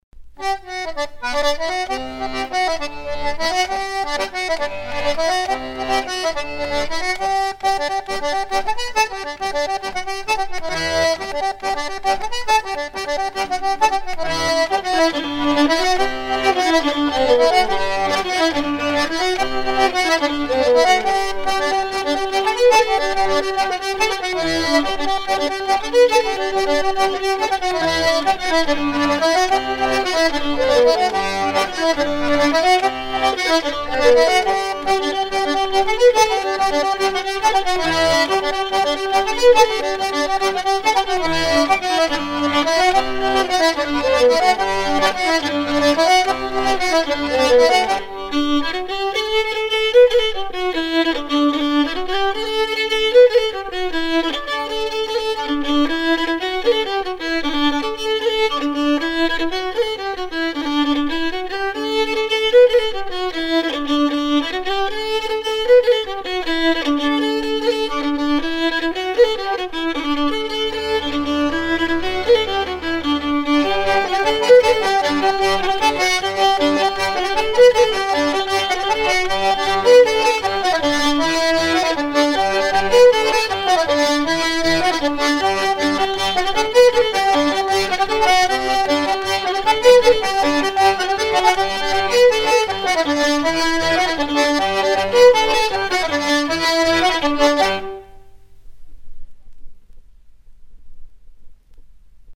Trois airs à danser le branle recueillis en 1975 sur l'île de Noirmoutier
danse : branle